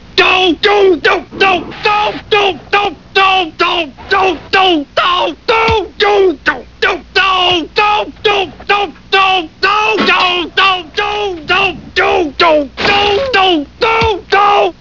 Homer 32 dohs